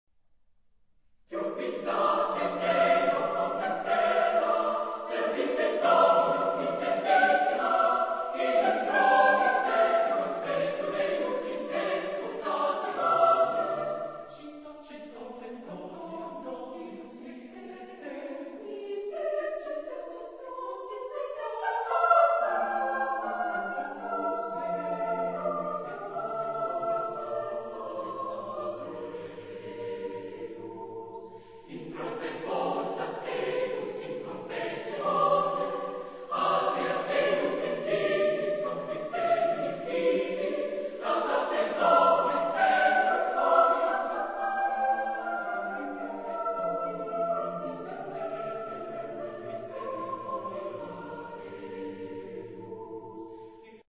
Genre-Style-Forme : Sacré
Type de choeur : SATB  (4 voix mixtes )
Tonalité : ré majeur
Consultable sous : 20ème Sacré Acappella